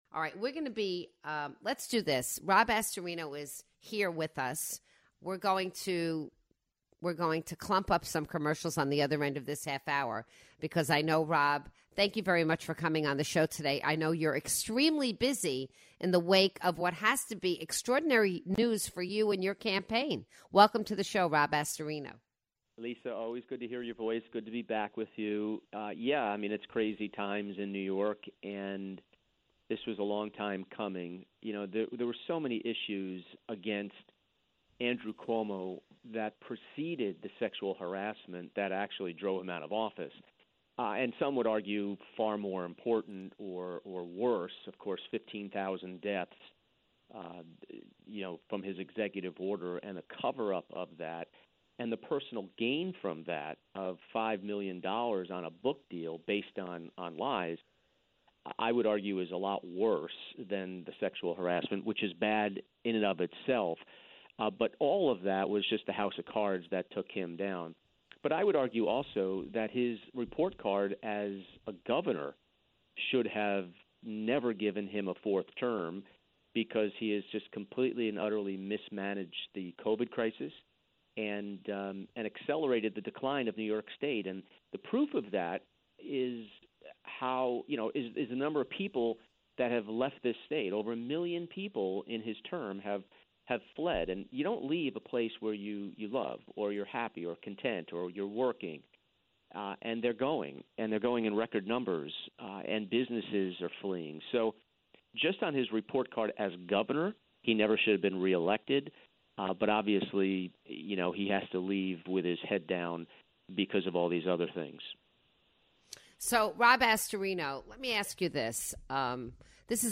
Rob Astorino calls into the show to explain why he's looking for the Republican nod to replace Andrew Cuomo as New York's Governor.